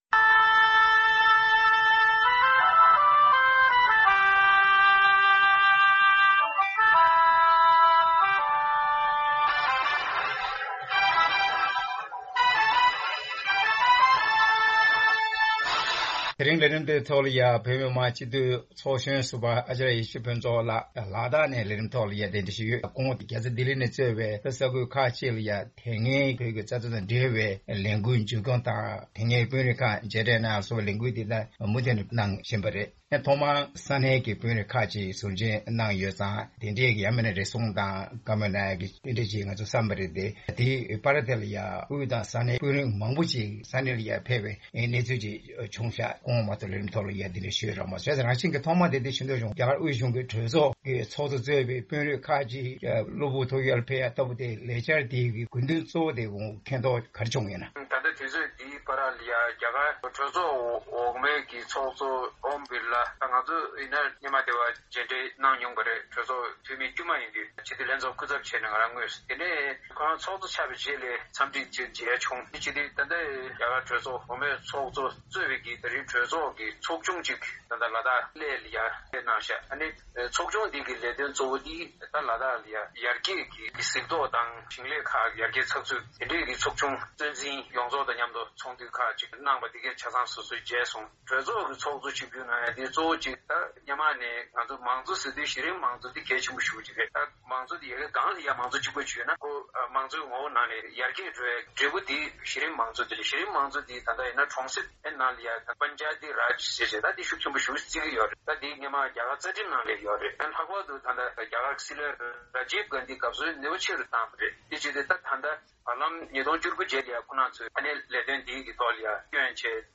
གླེང་མོལ་ཞུས་པ་ཞིག་གསན་རོགས་ཞུ།།